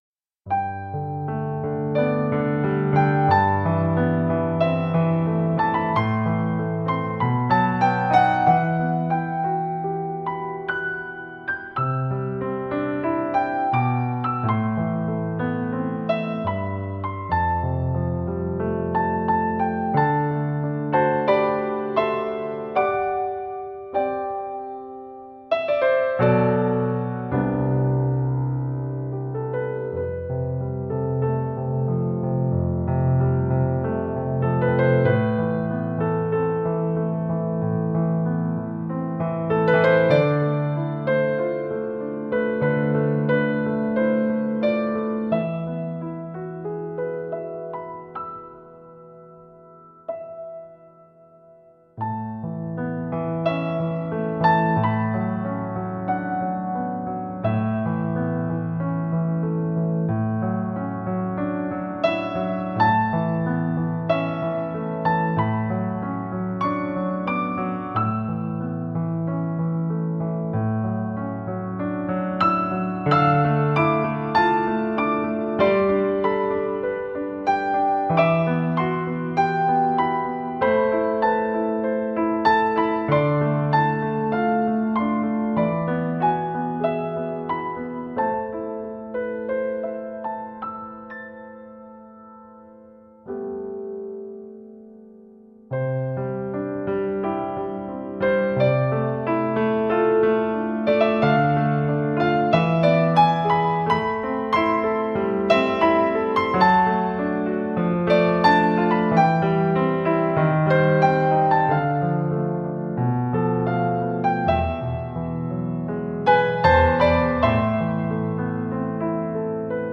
ピアノソロ